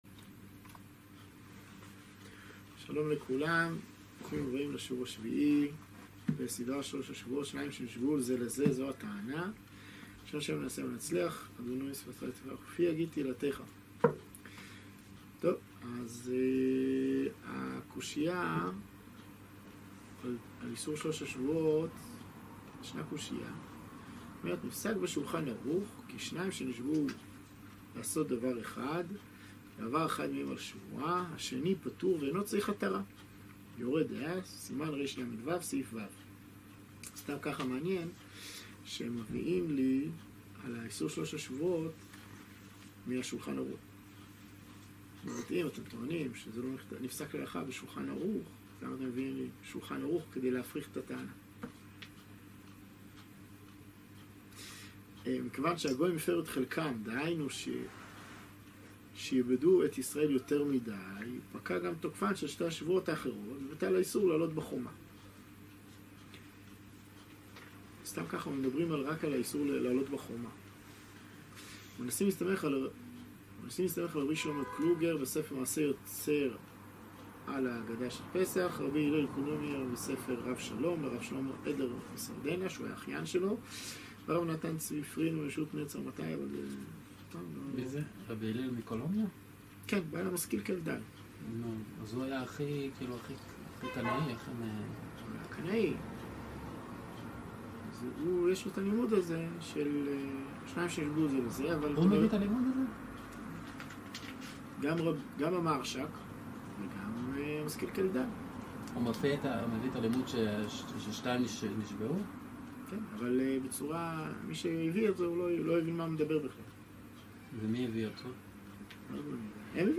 שיעור שביעי - שלוש השבועות ומה שמנסים להקשות שנים שנשבעו זה לזה